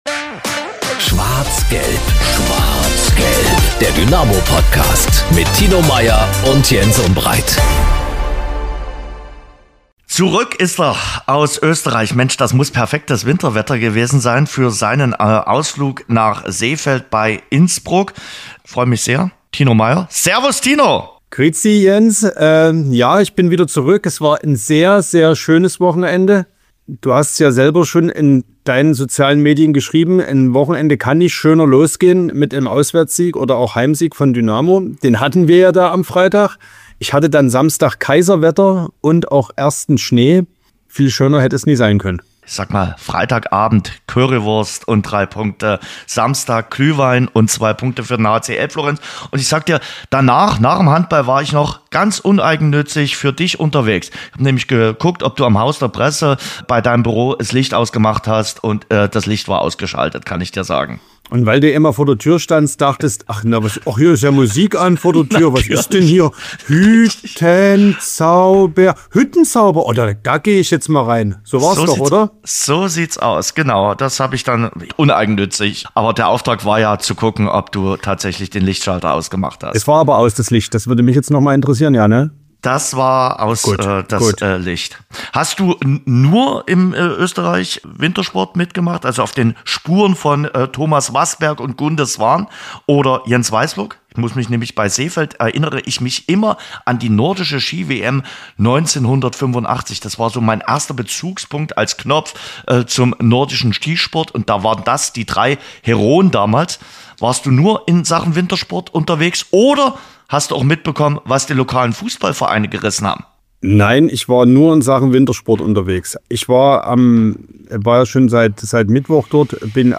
Ein weiterer Schwerpunkt der Folge sind die bundesweiten Fanproteste. Im Interview